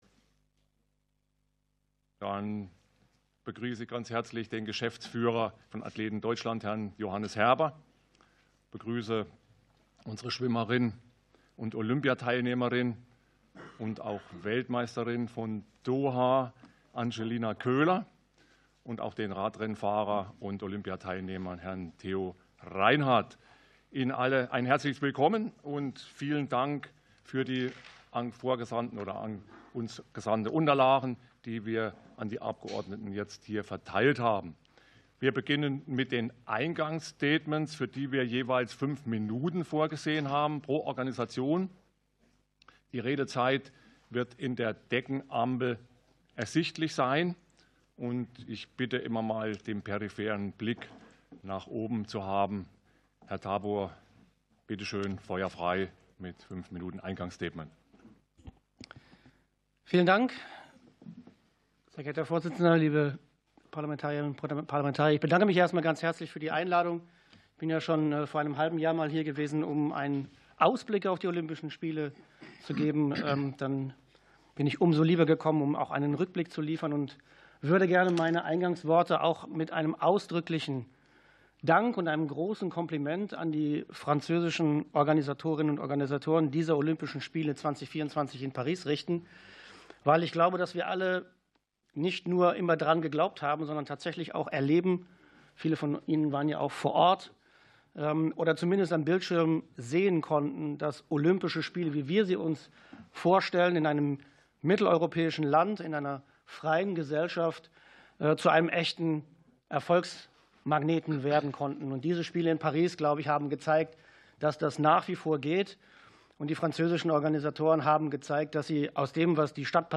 Ausschusssitzungen - Audio Podcasts